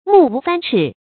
目無三尺 注音： ㄇㄨˋ ㄨˊ ㄙㄢ ㄔㄧˇ 讀音讀法： 意思解釋： 不把法制放在眼里。